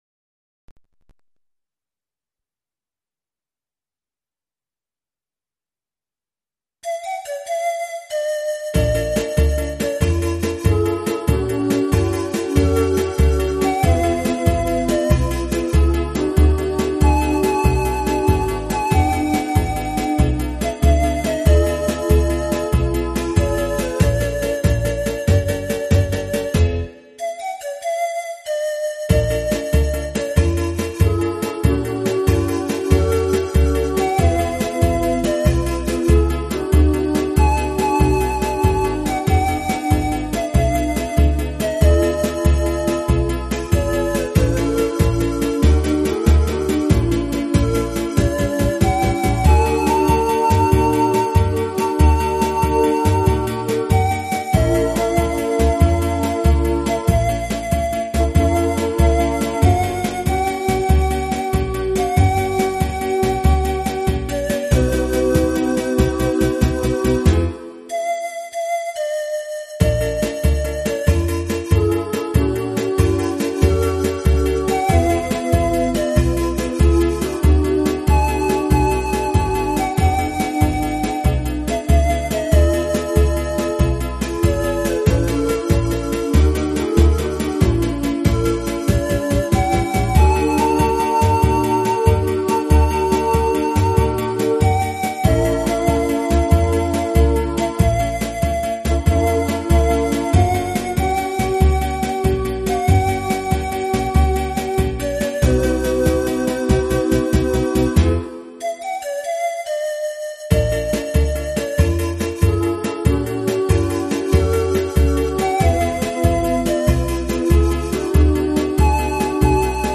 version instrumentale multipistes
au format MIDI Karaoke pro.